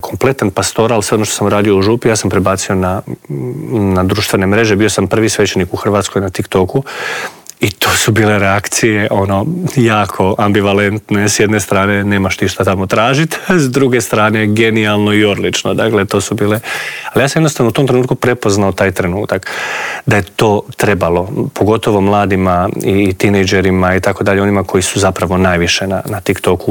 U Intervjuu Media servisa